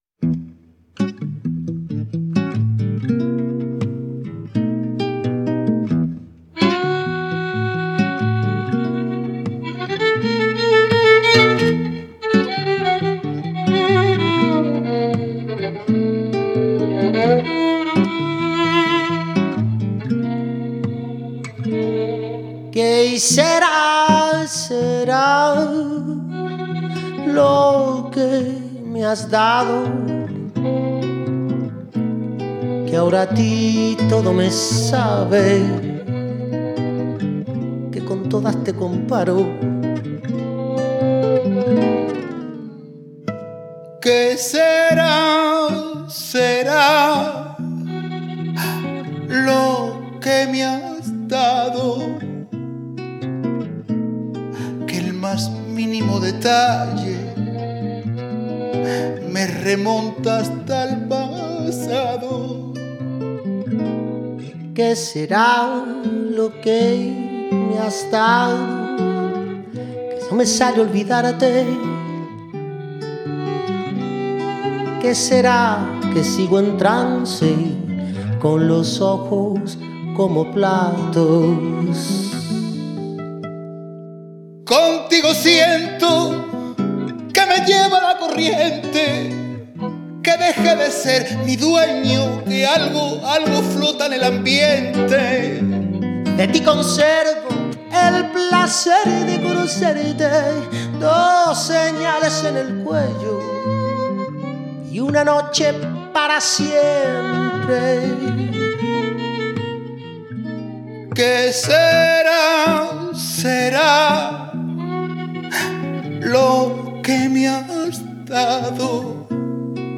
un disco de duetos